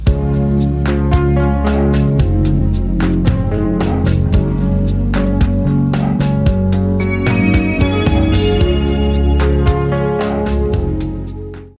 • Качество: 128, Stereo
без слов
romantic melody
Красивая романтическая мелодия на смс